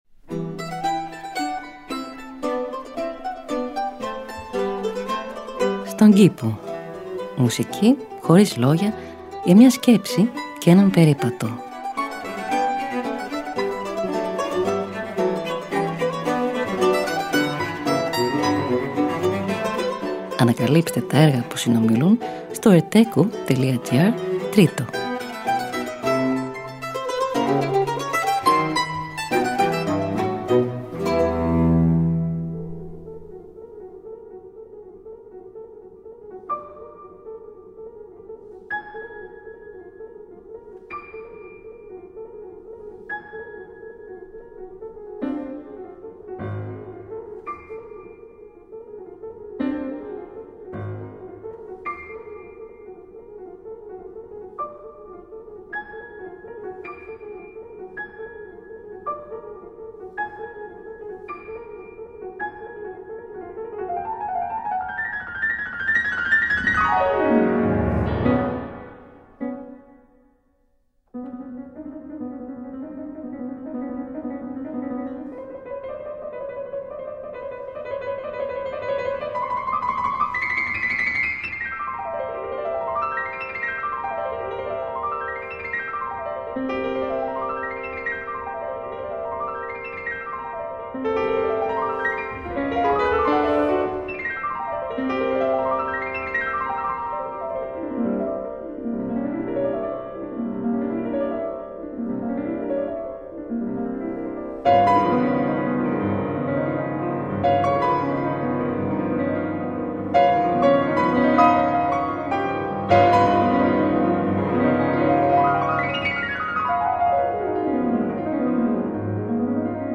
Μουσική Χωρίς Λόγια για μια Σκέψη και έναν Περίπατο.